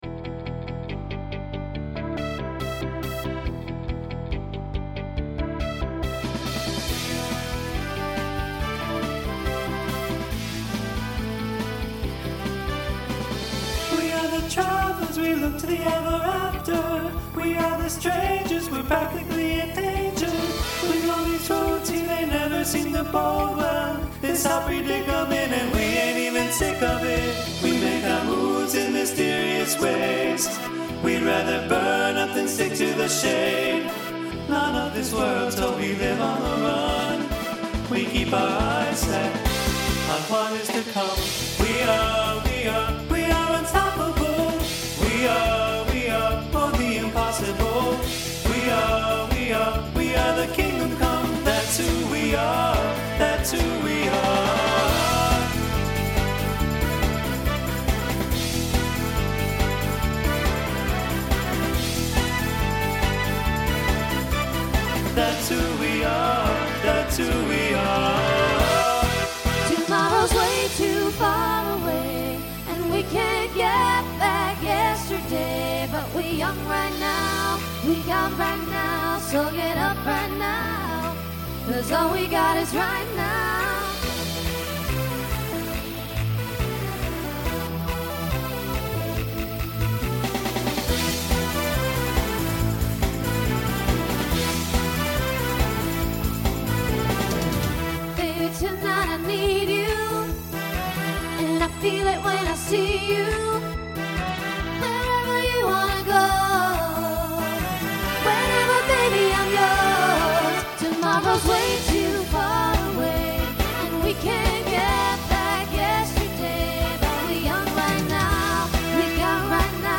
TTB/SSA/SATB
Voicing Mixed Instrumental combo
Pop/Dance , Rock